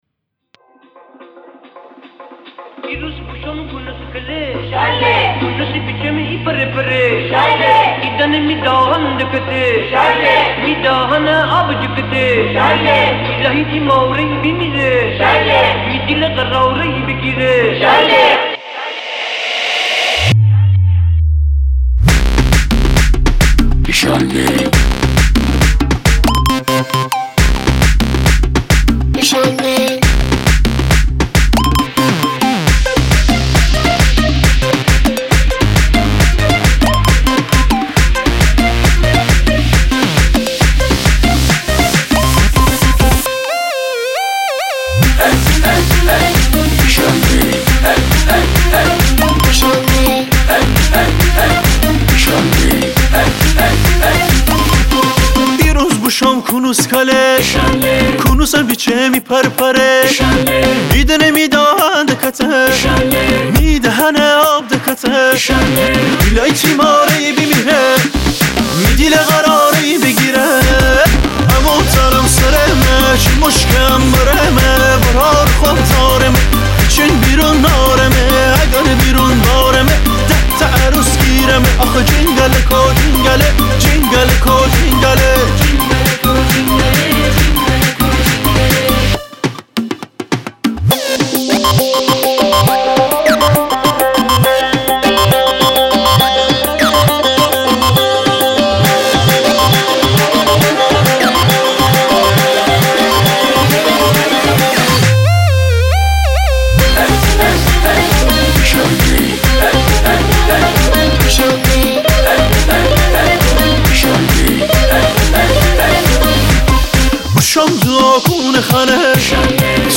آهنگ محلی
اجرای شاد